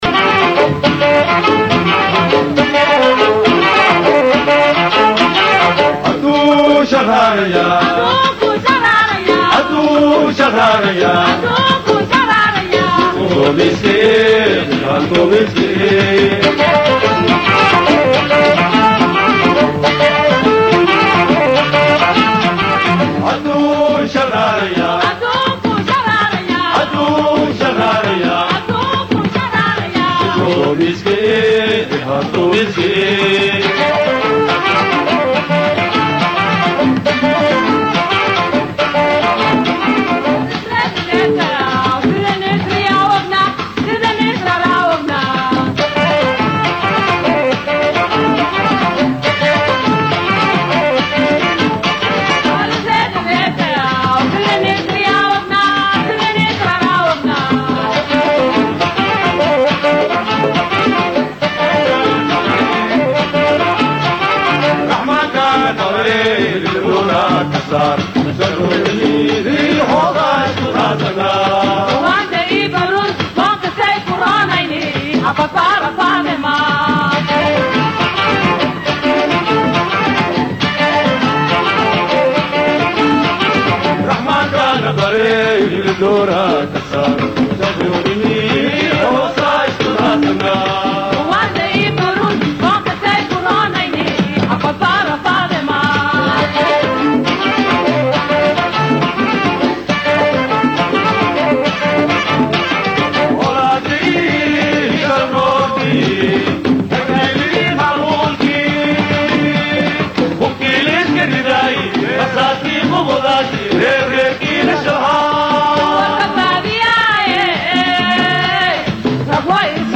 BAYDHABO–BMC:–Dhageystayaasha Radio Baidoa ee ku xiran Website-ka Idaacada Waxaan halkaan ugu soo gudbineynaa Barnaamijka Dabagalka Wararka iyo Wareysiyada ee ka baxay Radio Baidoa. waxaa soo jeedi…